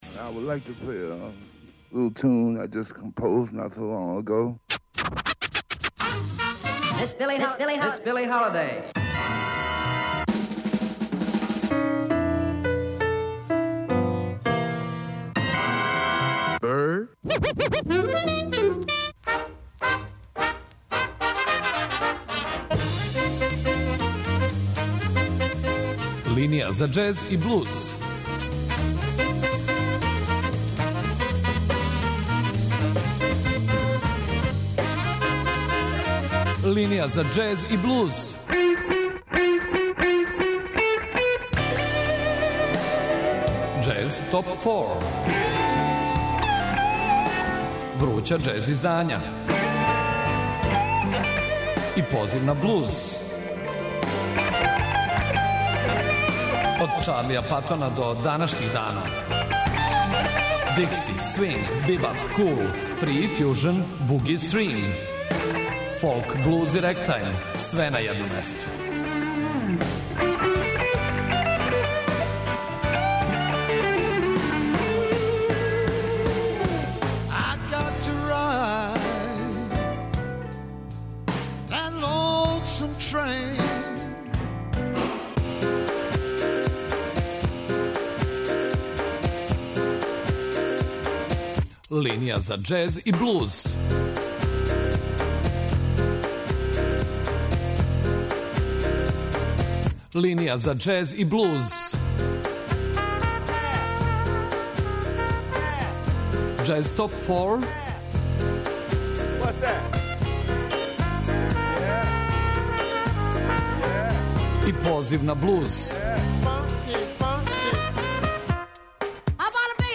Свако издање биће представљено у оквиру 25-минутног блока, уз кратку причу и музику са албума.